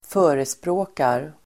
Uttal: [²f'ö:resprå:kar]